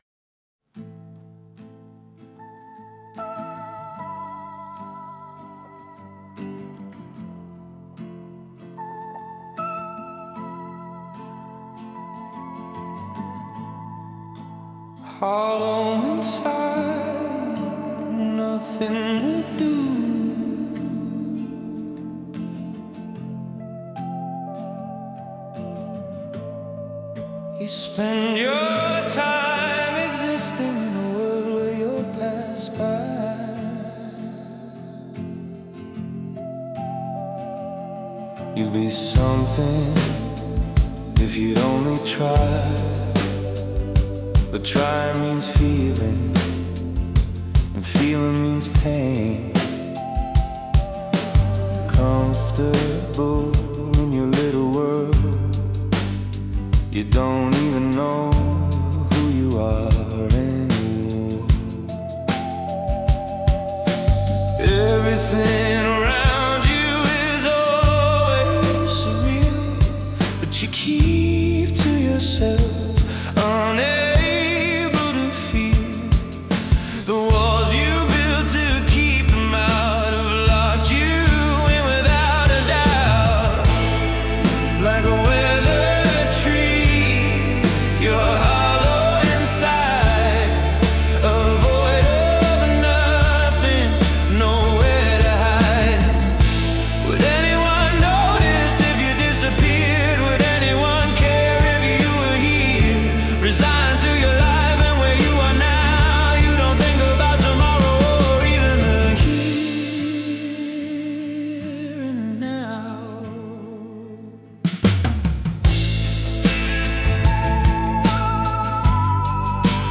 Indie Rock • Emptiness • Loss • Introspection